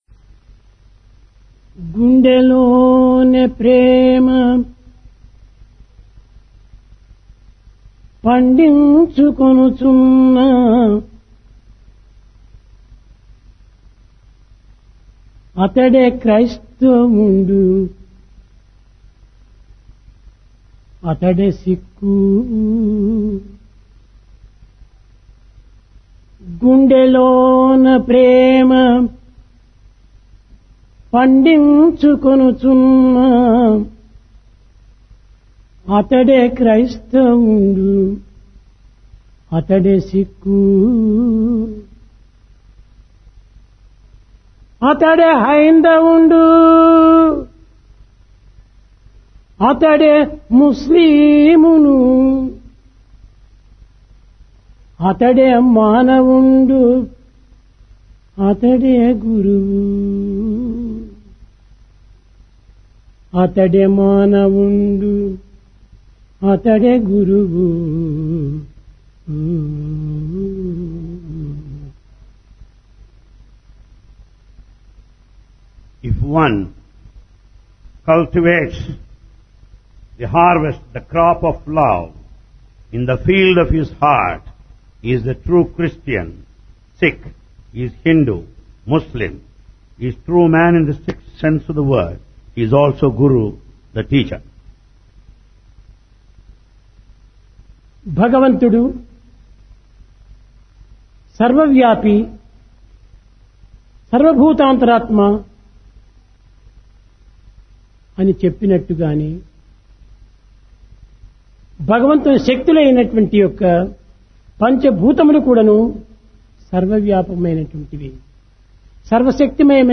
Divine Discourse of Bhagawan Sri Sathya Sai Baba, Sri Sathya Sai Speaks, Vol 34 (2001) Date: 05 July 2001 Occasion: Guru Purnima